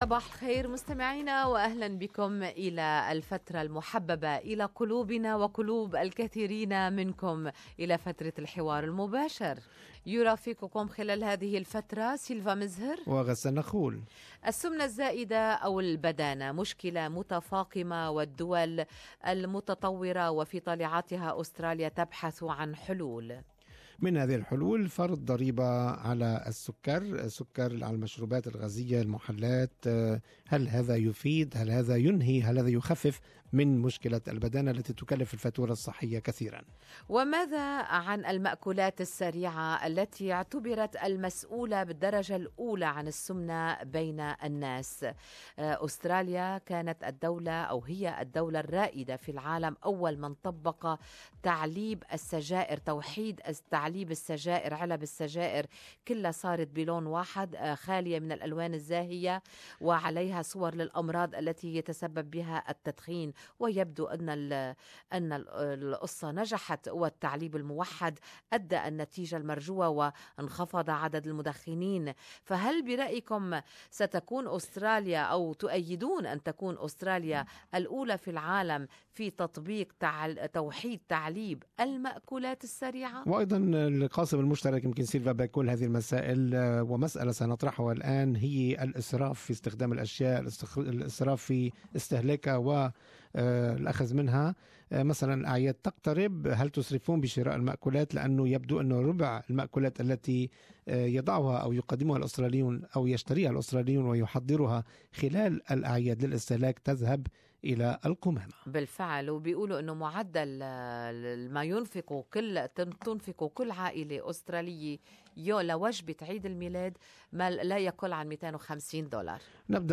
Talkback: Will Australia impose Sugar Tax on Soft Drinks?